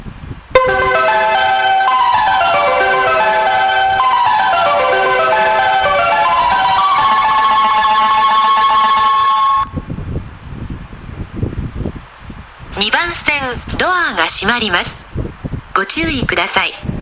スピーカーは１〜４番線ともに小ボスが使用されており音質はとてもいいと思います。
主な収録場所はホーム端なら人がいなく雑音も入りにくいので お勧めします。
発車メロディー余韻切りです。